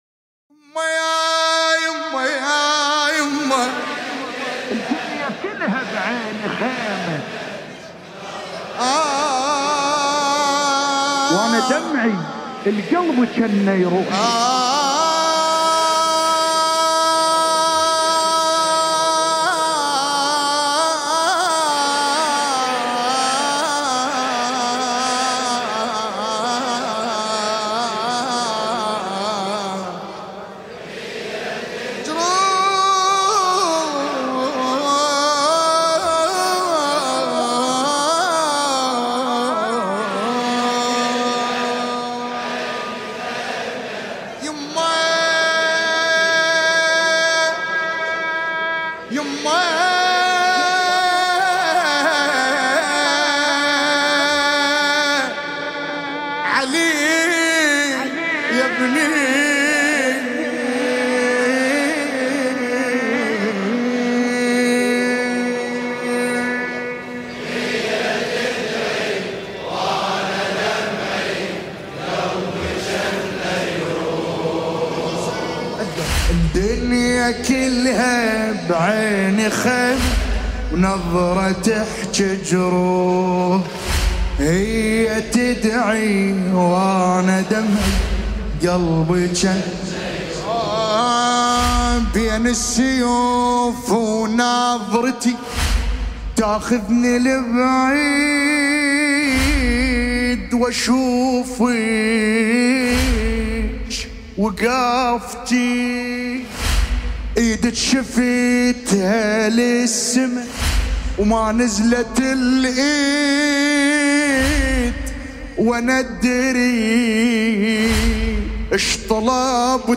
شهر محرم الحرام 1447 هـ